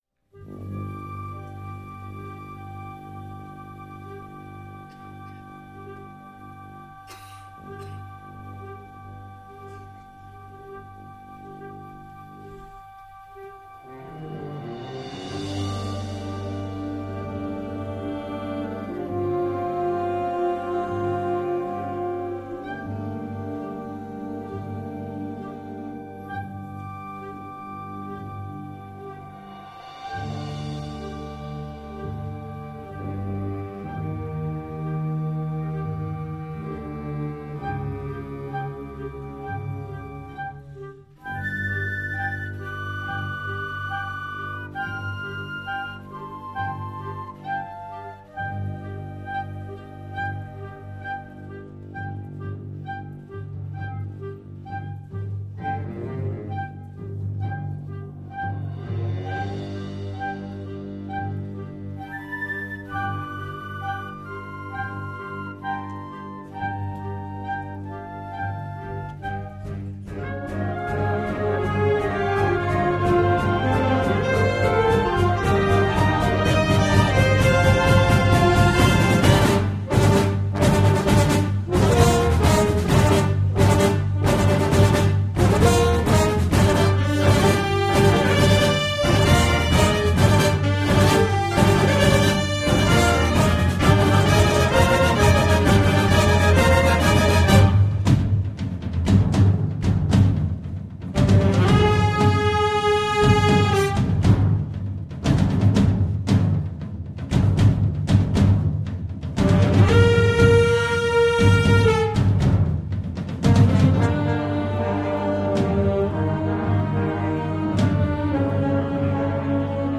Ecoute du Concert de Noël 2013 en live de l'Orchestre de Gaillard
Concert de Noël 2013 en live depuis l'Espace Louis Simon de Gaillard par l'Orchestre de Gaillard.